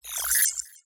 Incoming Message 5.wav